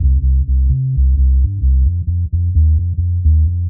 Index of /musicradar/dub-designer-samples/130bpm/Bass
DD_PBass_130_C.wav